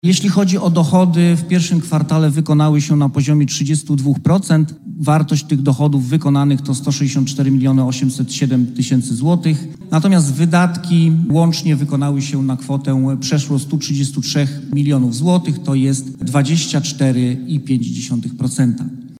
Natomiast wydatki łącznie wykonały się na kwotę przeszło 133 milionów złotych, to jest 24,5 procenta” – mówi Łukasz Wilkosz, Skarbnik Miasta Stargard.